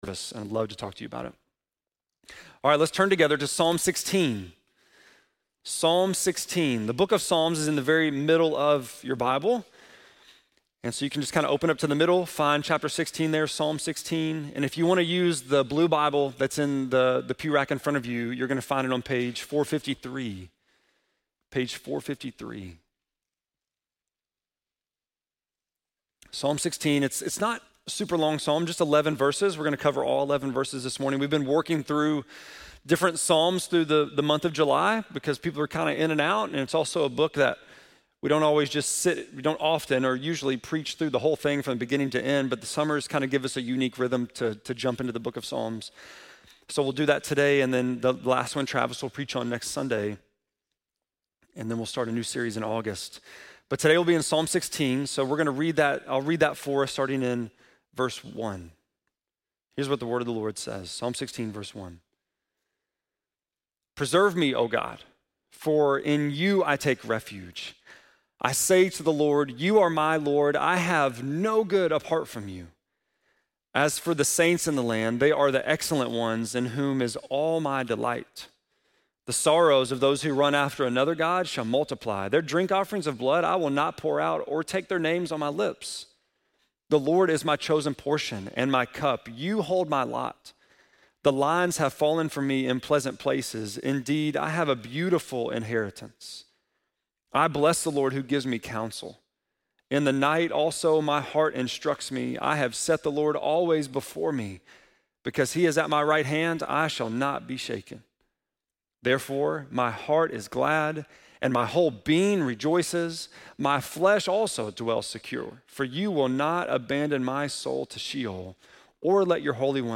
7.20-sermon.mp3